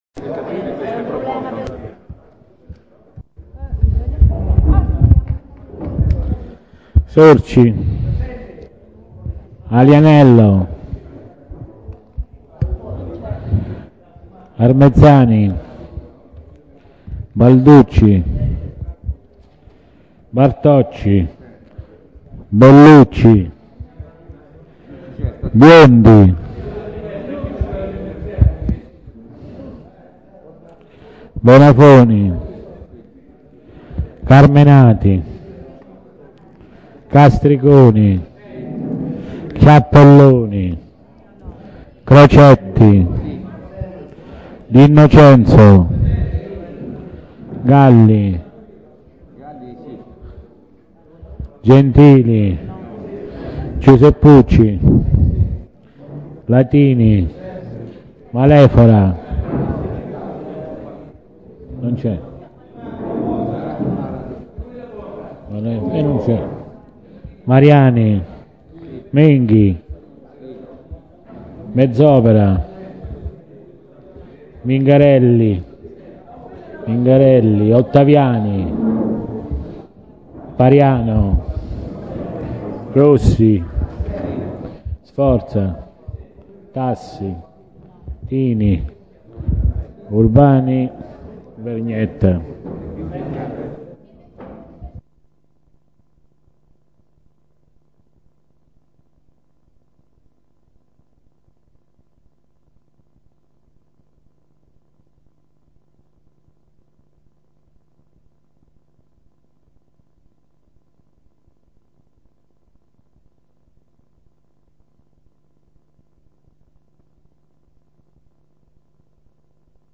Seduta del 20 dicembre 2007
Seduta del 20 dicembre 2007 Ai sensi dell`art. 20, comma 7, dello Statuto Comunale e dell`articolo 14 del regolamento consiliare il Consiglio Comunale e` convocato presso Palazzo Chiavelli - sala consiliare (Piazza del Comune, 1) GIOVEDI` 20 DICEMBRE 2007 alle ore 9 con termine alle ore 13,30 per il Consiglio Comunale Ordinario, con la trattazione degli argomenti iscritti nell`allegato ordine del giorno; alle ore 15,30 per il Consiglio relativo alle PROBLEMATICHE DEL LAVORO come da richiesta formulata dalla maggioranza .